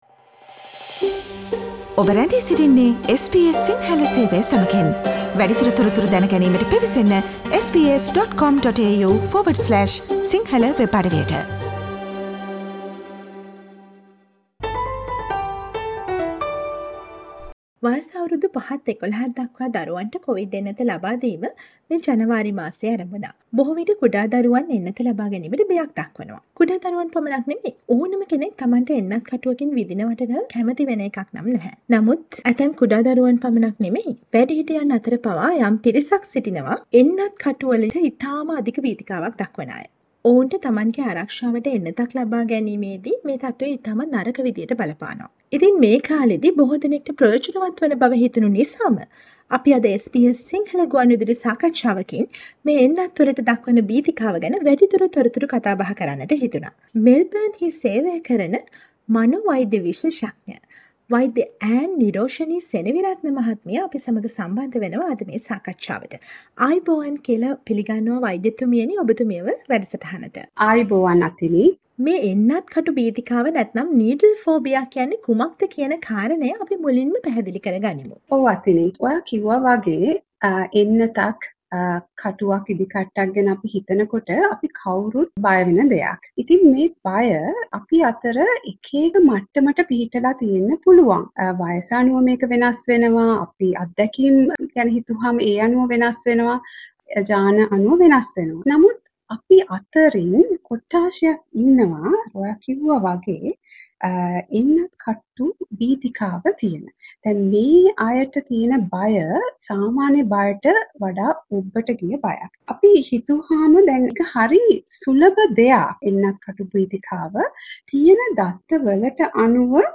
එන්නත් කටු සදහා භීතිකාවෙන් පෙලෙන දරුවන්ට කොවිඩ් එන්නත සාර්ථකව ලබා දීම වෙනුවෙන් SBS ගුවන් විදුලිය සිදු කළ සාකච්ඡාවට සවන් දෙන්න ඉහත ඡායාරූපය මත ඇති speaker සලකුණ මත ක්ලික් කරන්න